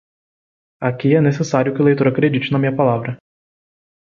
Pronunciado como (IPA)
/lejˈtoʁ/